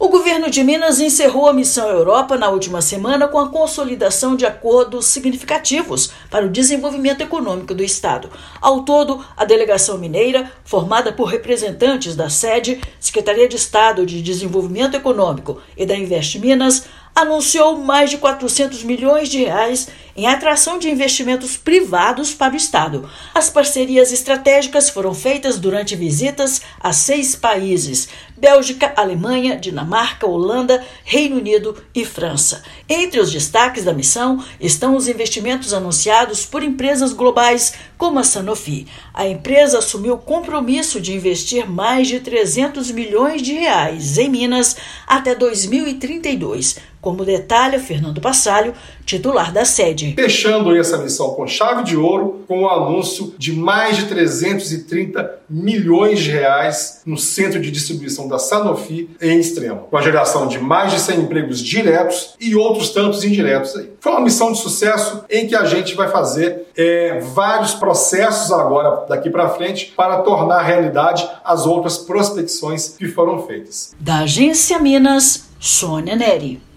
Parcerias estratégicas foram divulgadas por delegação mineira durante visita a seis países europeus. Ouça matéria de rádio.